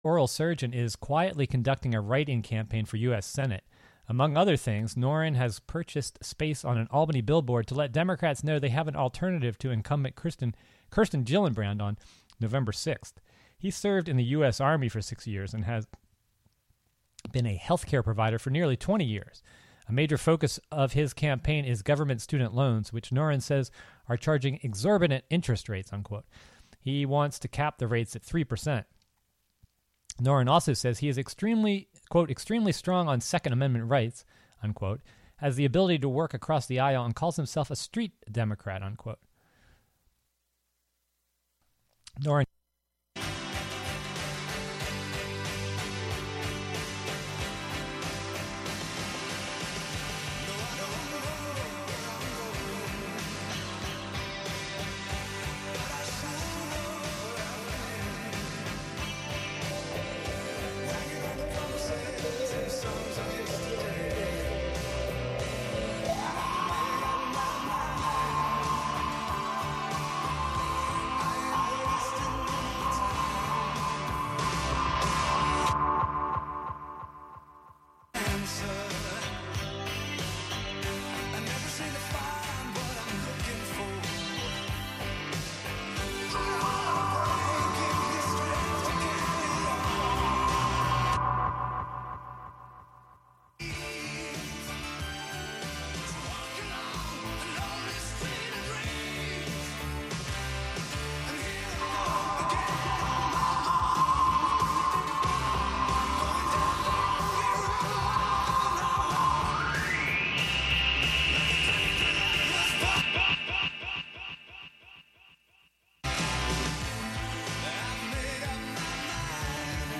Live from Brooklyn, NY